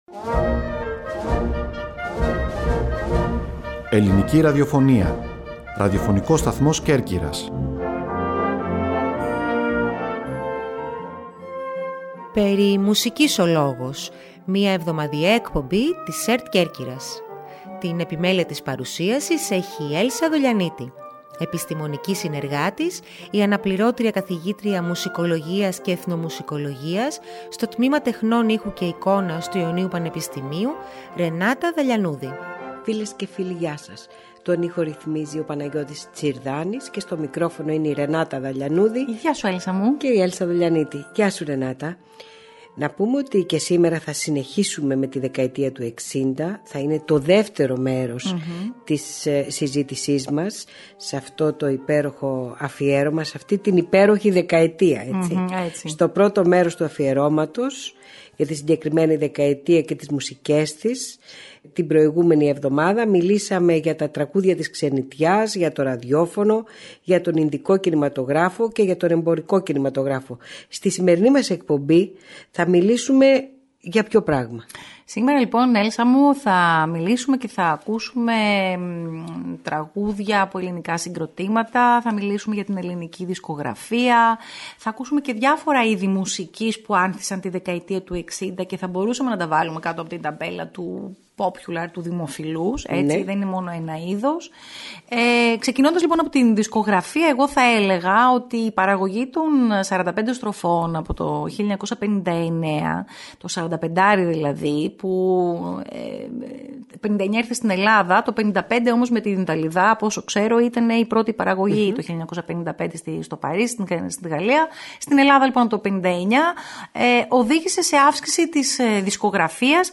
Στην εκπομπή αυτή ακούγονται τραγούδια από ελληνικά συγκροτήματα και συζητάμε για την ελληνική δισκογραφία, αλλά και για διάφορα είδη μουσικής που άνθισαν κατά τη δεκαετία του ’60.